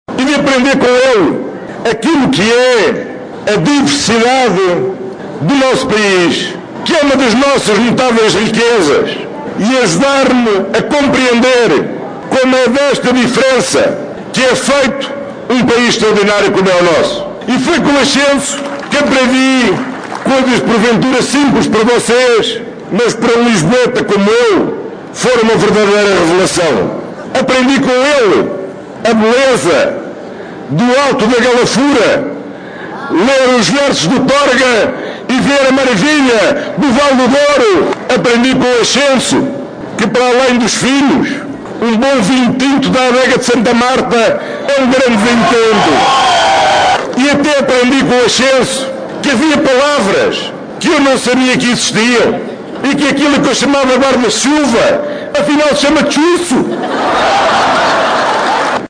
O Largo da Capela Nova em Vila Real é o lugar escolhido pelos socialistas para os seus comícios por entenderem que é um sinónimo de vitória.